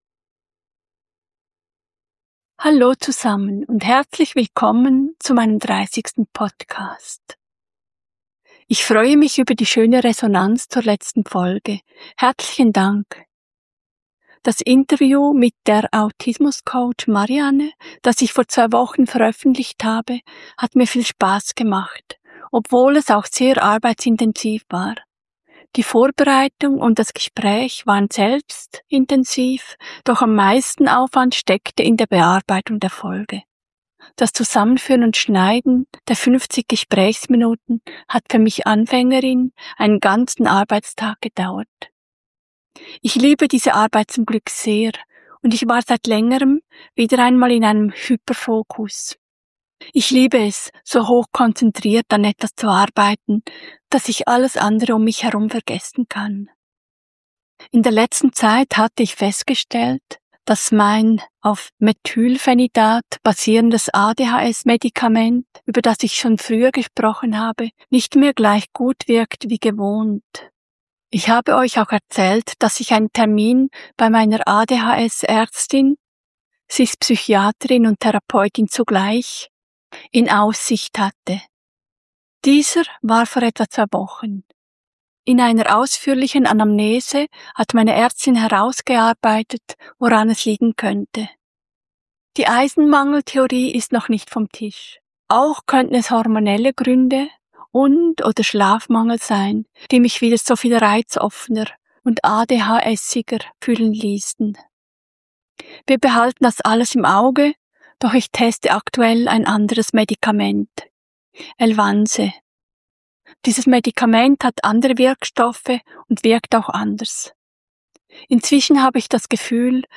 Neben mir berichten zwölf Menschen in dieser Folge von ihren Erfahrungen. Was sie anstrengt, was sie erschöpft.